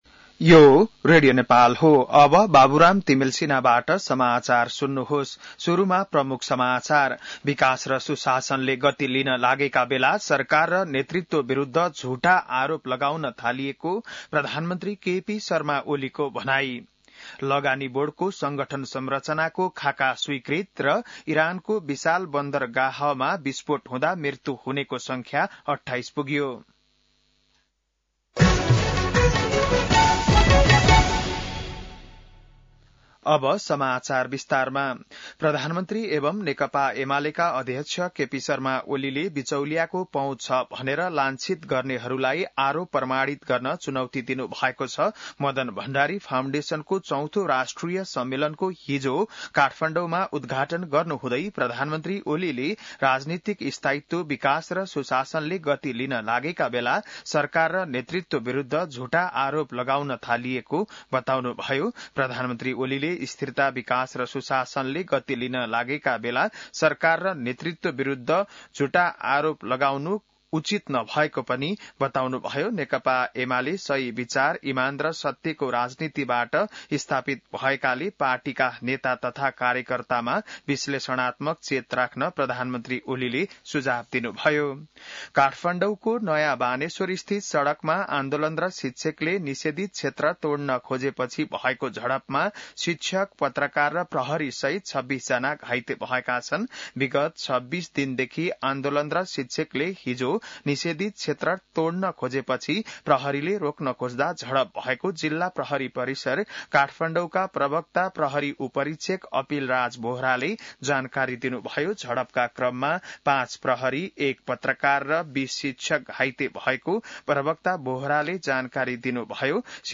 बिहान ९ बजेको नेपाली समाचार : १५ वैशाख , २०८२